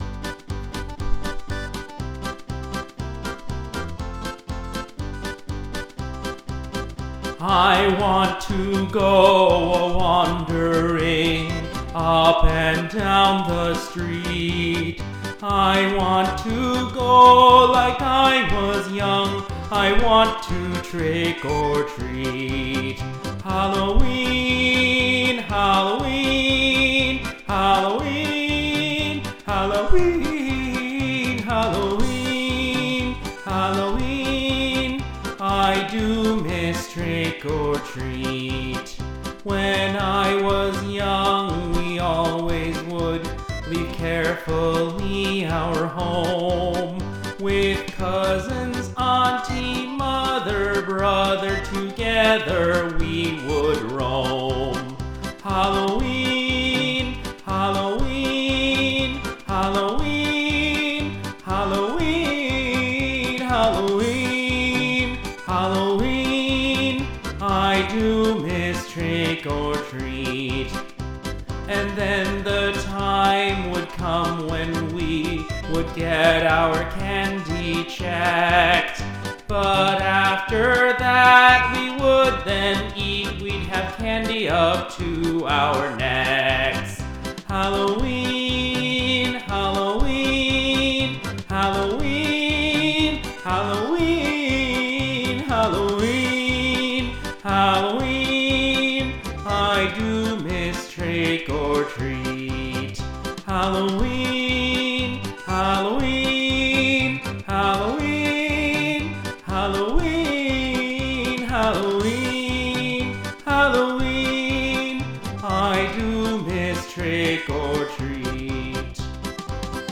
bittersweet one-take song about real-world Halloween memories: I Do Miss Trick or Treat.